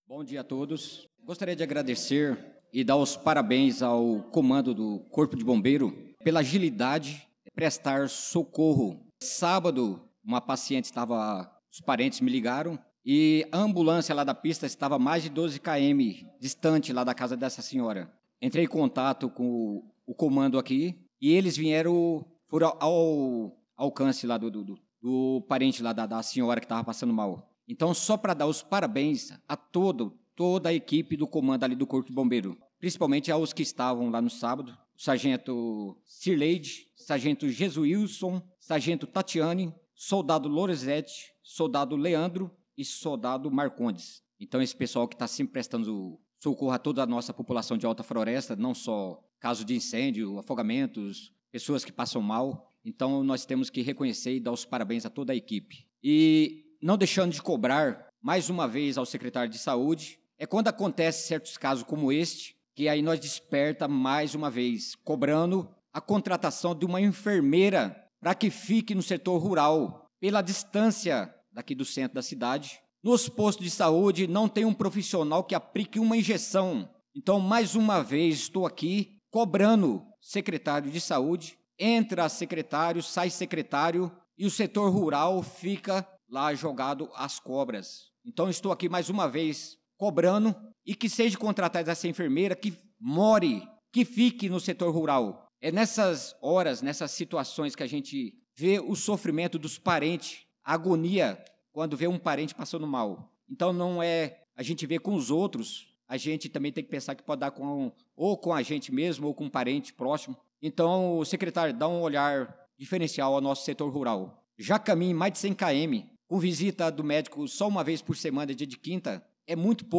Pronunciamento do vereador Naldo da Pista na Sessão Ordinária do dia 25/03/2025